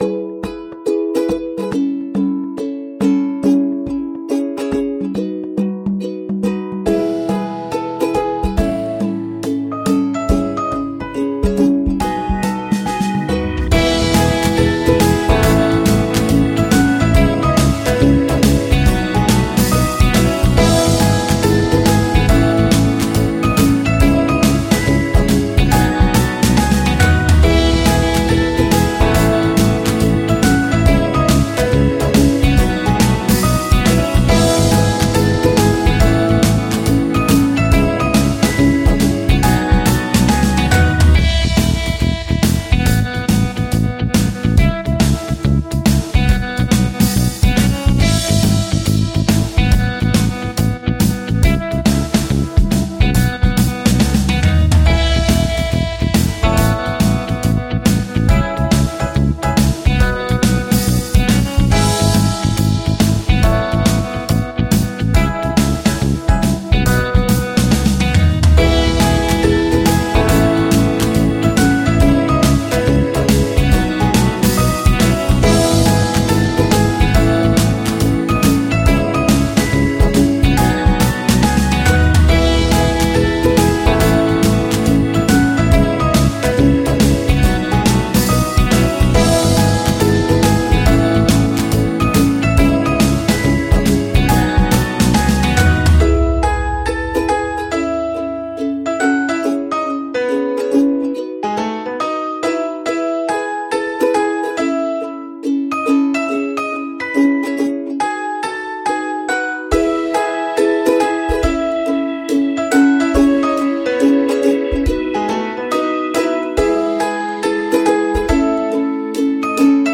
Медленная добрая музыка для фона